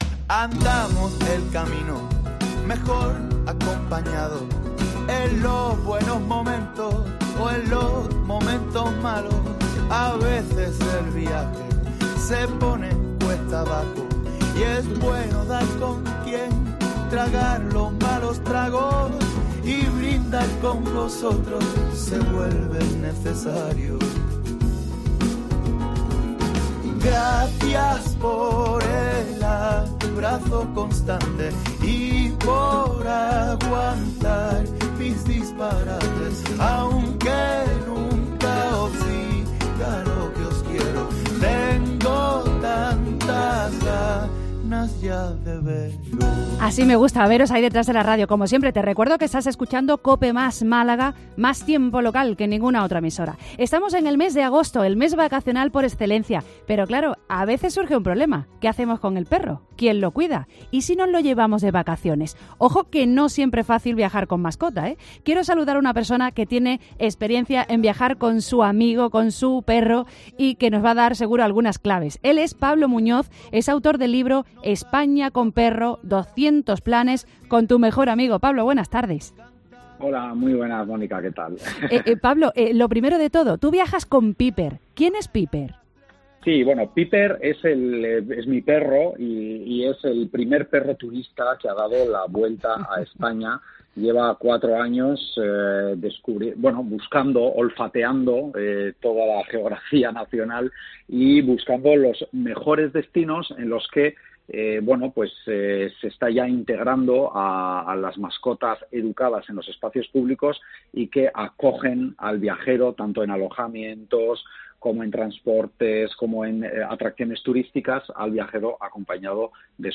MÁLAGA CAPITAL Durante su entrevista en Cope Málaga destacó el Museo Automovilístico y de la Moda entre los lugares a los que pueden acceder las personas con sus perros.